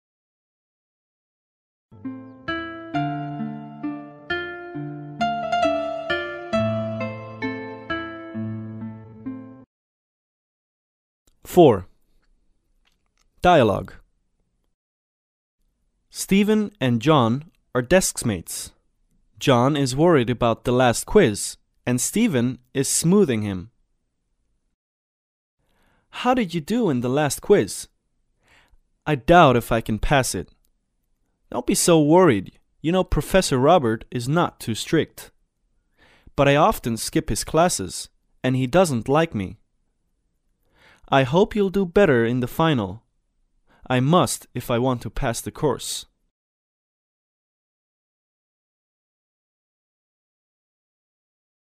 对话